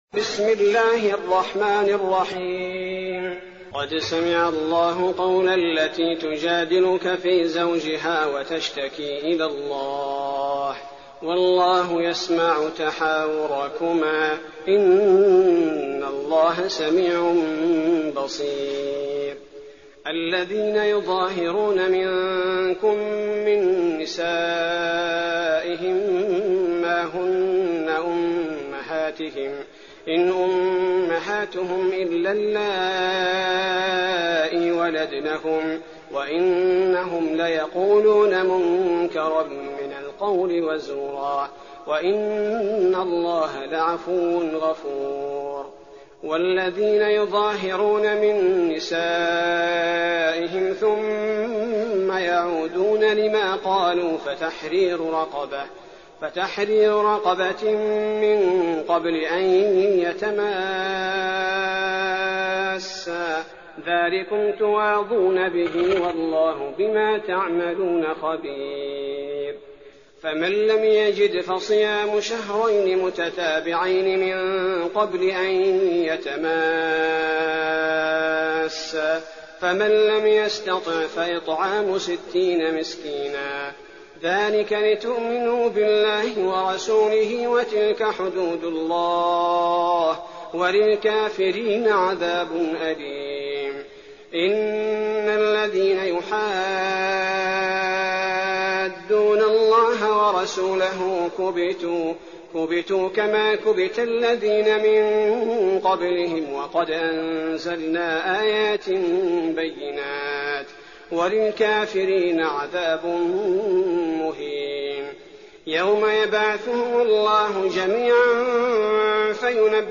تراويح ليلة 27 رمضان 1419هـ من سورة المجادلة الى سورة الصف Taraweeh 27th night Ramadan 1419H from Surah Al-Mujaadila to As-Saff > تراويح الحرم النبوي عام 1419 🕌 > التراويح - تلاوات الحرمين